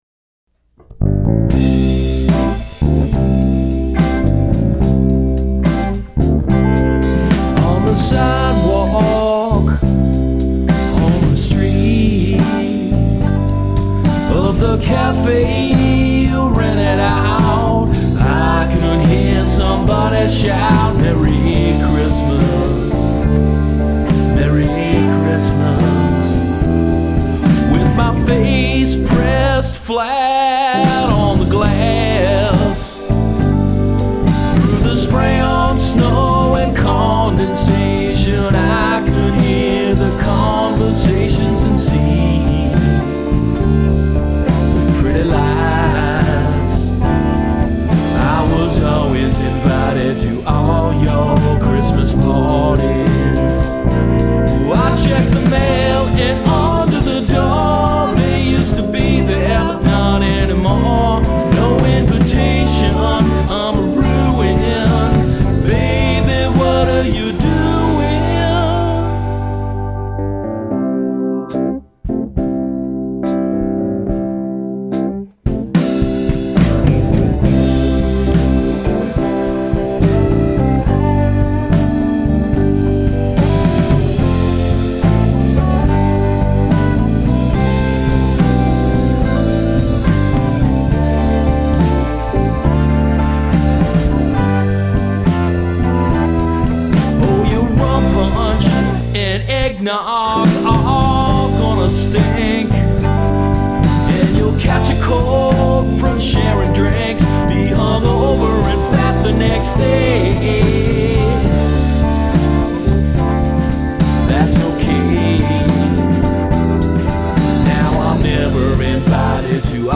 Well, here they are in all their ancient, weirdly mixed and overstuffed glory.